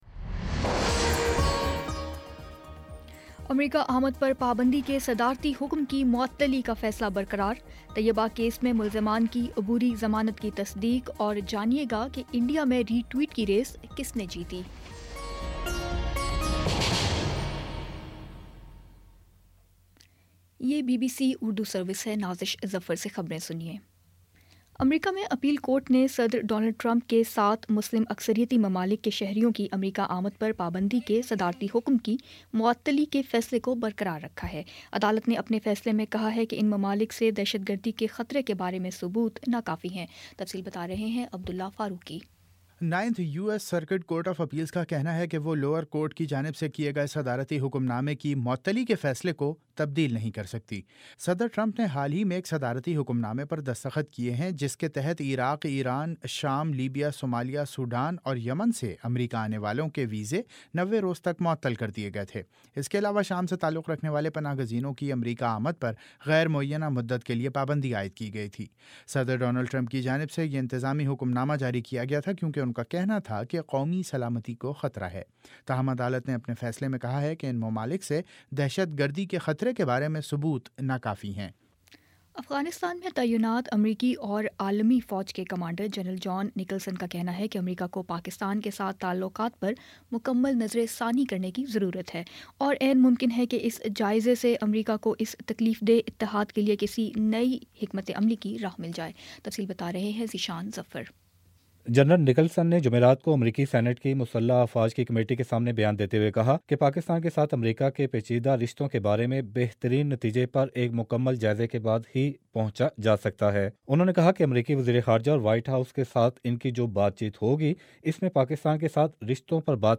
فروری 10 : شام پانچ بجے کا نیوز بُلیٹن